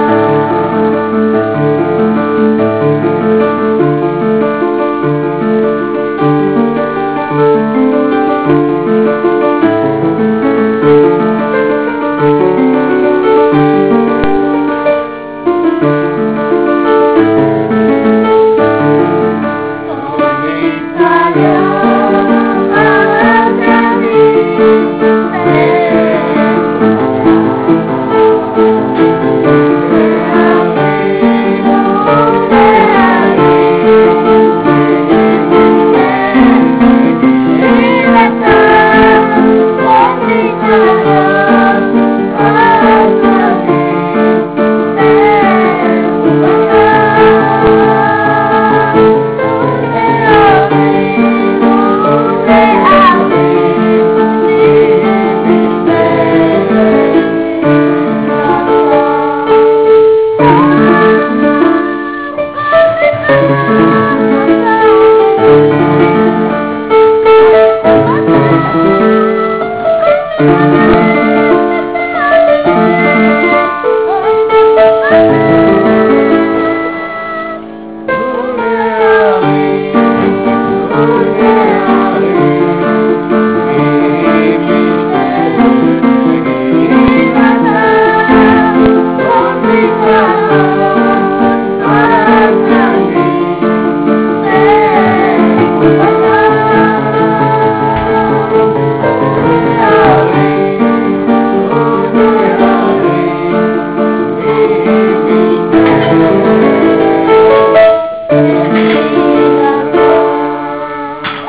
אחותי הלחינה את השיר הזה לכבוד יום חתונתה אני על הפסנתר ואחי ואבי היקר שרים..